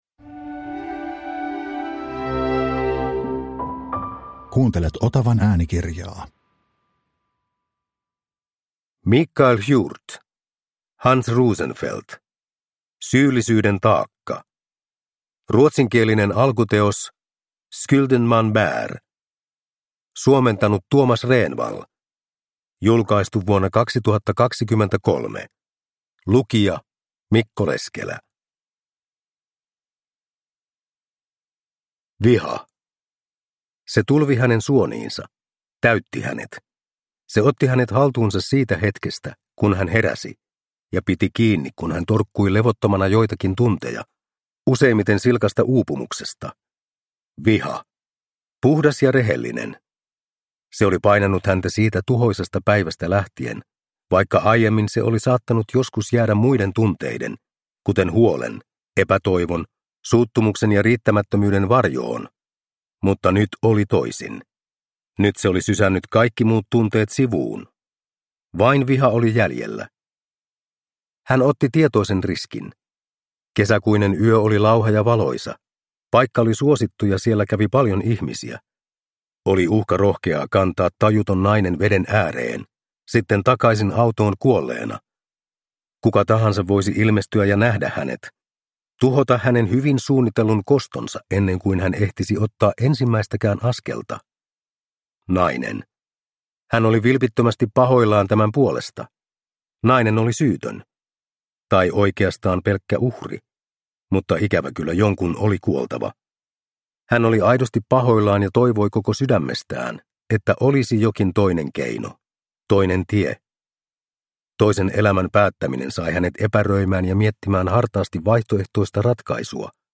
Syyllisyyden taakka – Ljudbok – Laddas ner